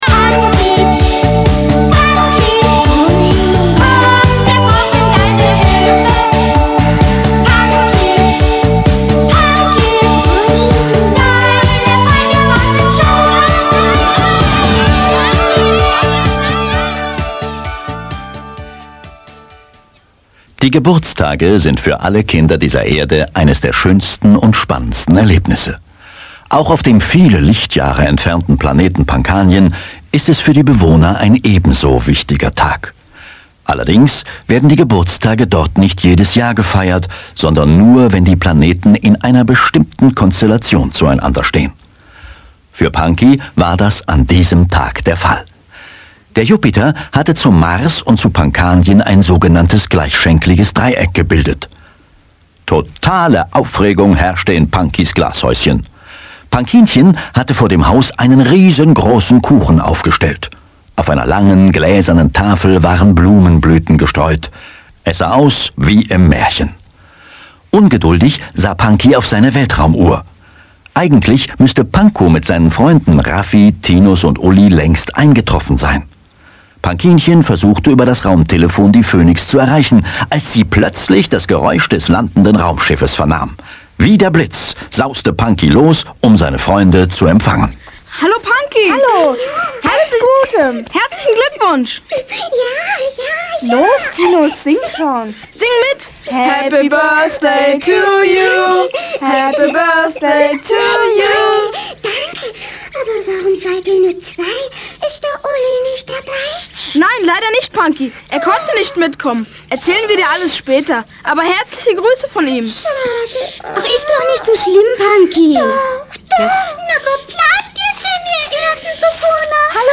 Panki: Alle Folgen der Kinderh�rspiele